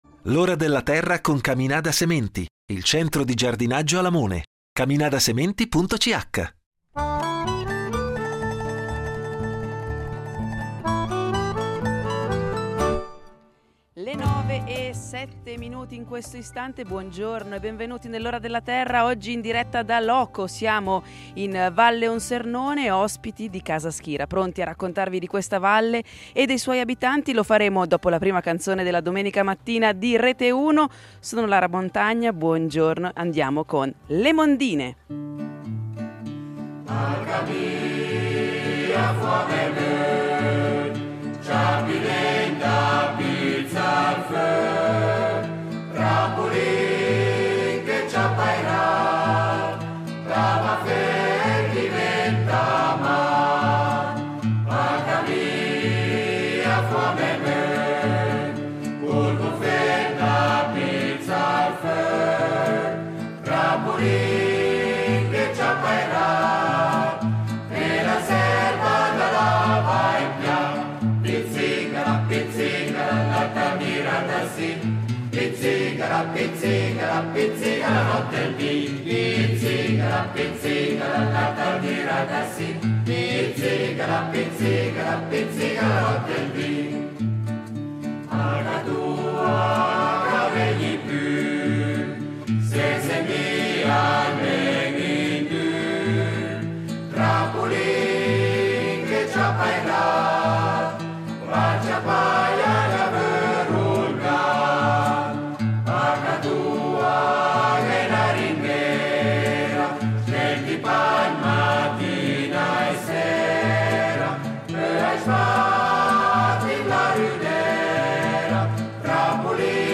Naturalmente non mancheranno gli esperti del programma che risponderanno alle vostra domande da casa.